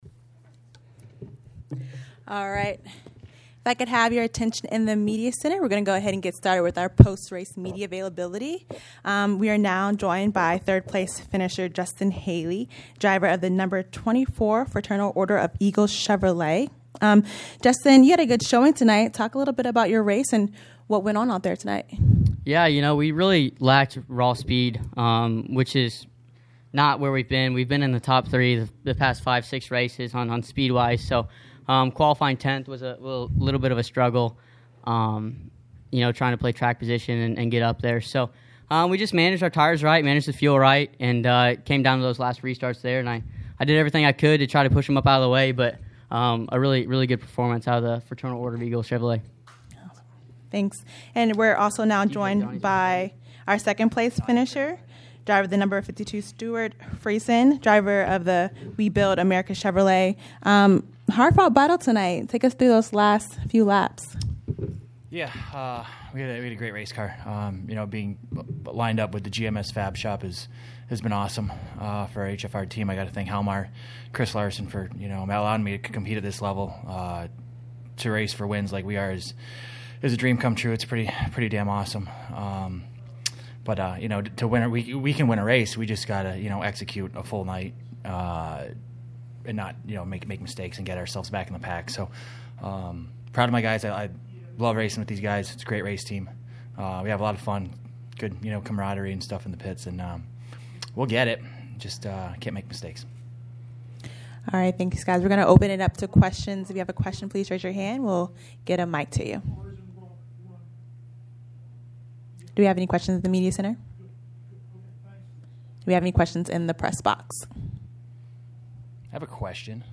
Media Center Interviews:
Second-place finisher Stewart Friesen and third-place finisher Justin Haley –